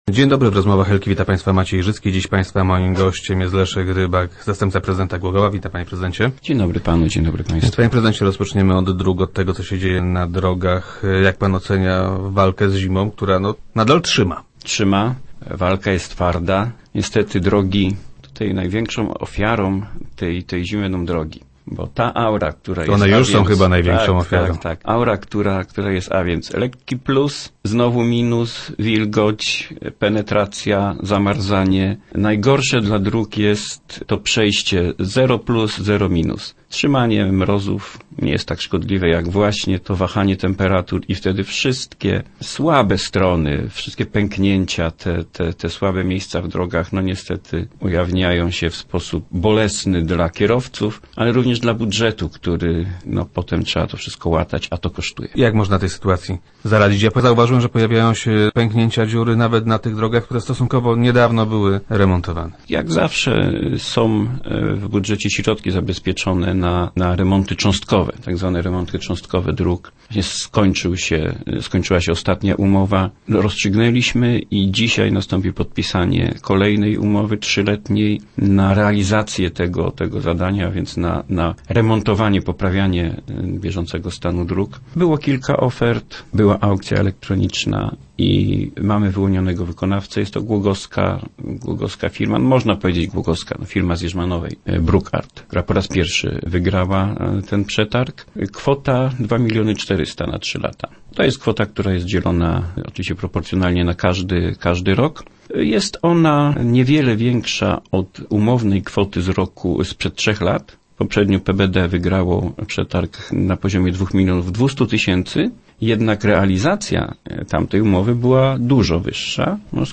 Chcemy tego uniknąć i właśnie dlatego w umowie znalazły się zapisy o karach dla wykonawcy za takie prowadzenie prac - mówi wiceprezydent Rybak, który był gościem Rozmów Elki.